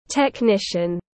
Kỹ thuật viên tiếng anh gọi là technician, phiên âm tiếng anh đọc là /tekˈnɪʃn̩/.